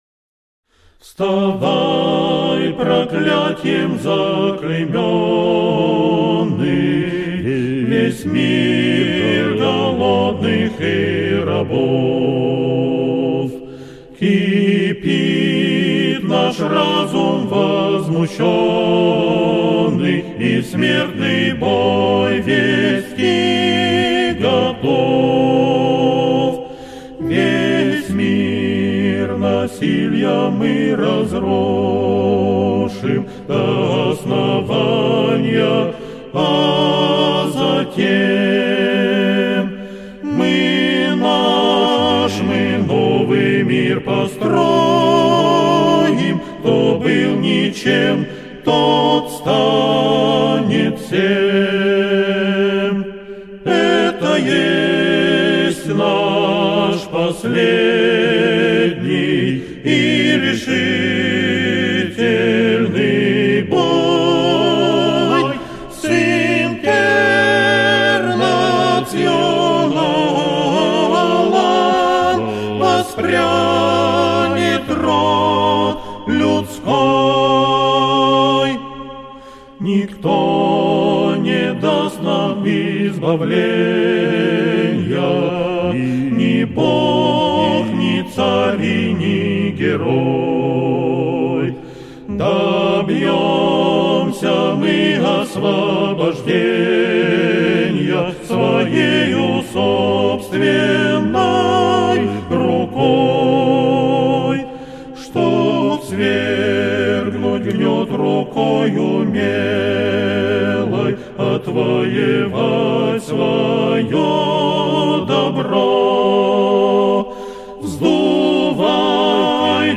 гимн
А капелла в русской традиции